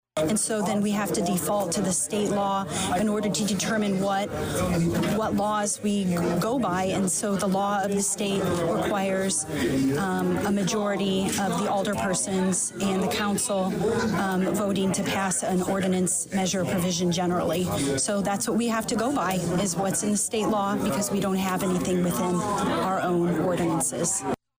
Alderwoman Eve Ludwig stated that Danville is a home rule community, and therefore should have its own law on this at some point, no matter which way it goes.